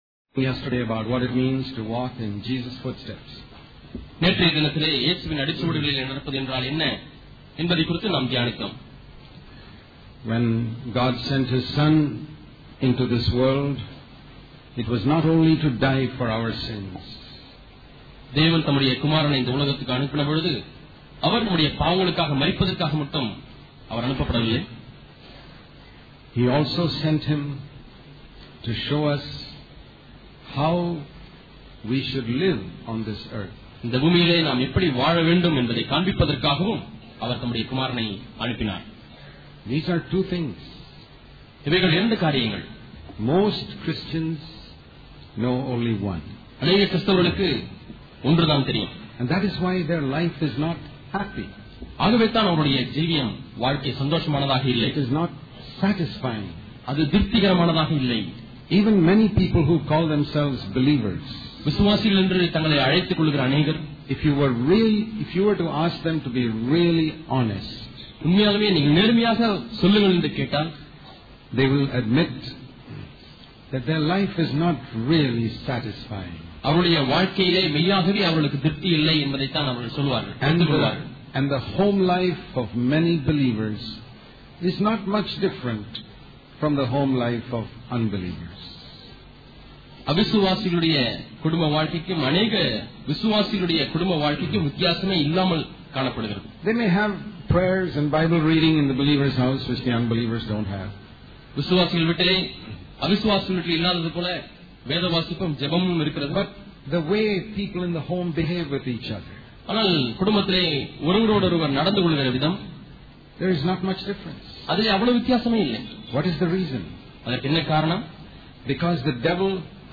Freedom from the Clutches of Self Public Meetings- Tuticorin and Kovilpatti